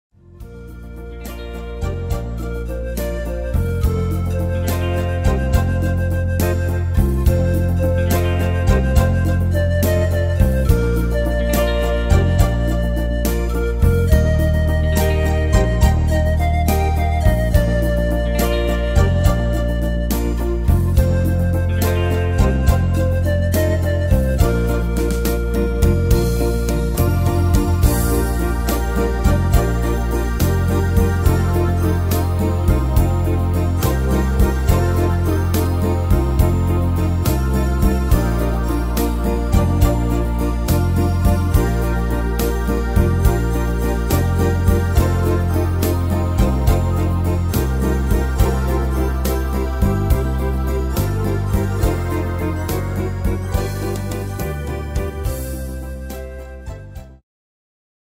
Tempo: 70 / Tonart: Bb-Dur